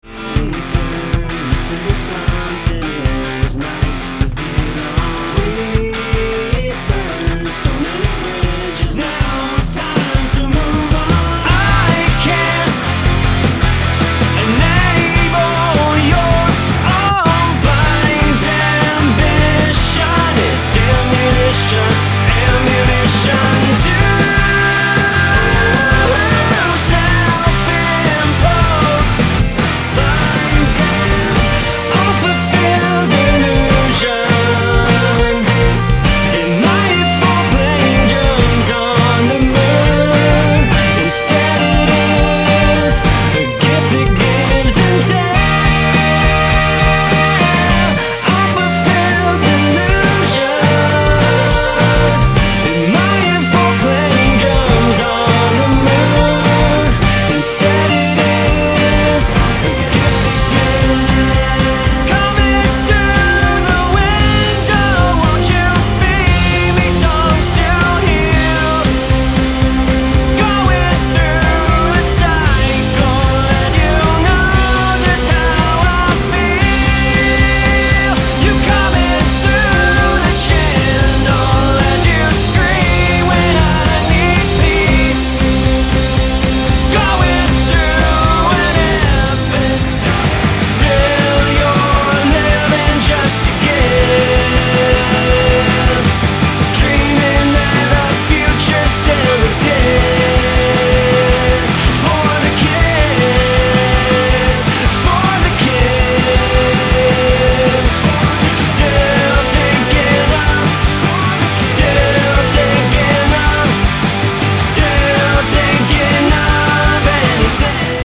indie-rock band
vocals and drums
guitar and bass
polished pop vocals